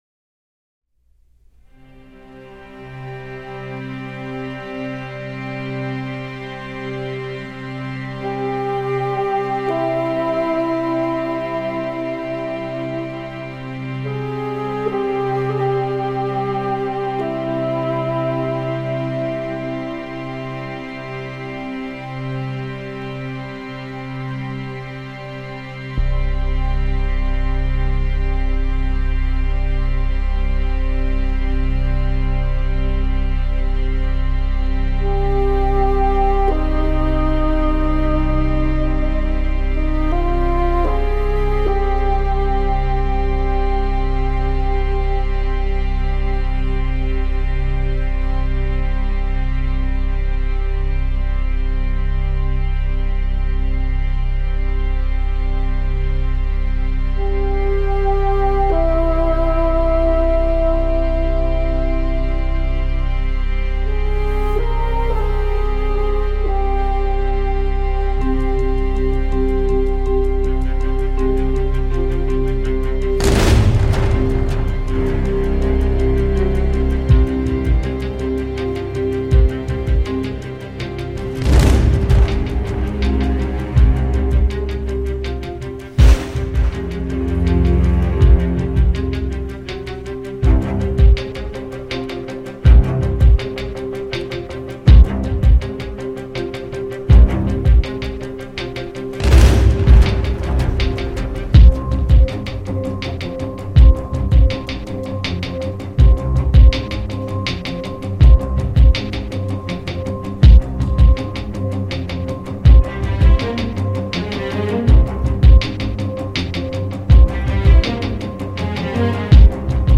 Svelte, nerveux, furtif.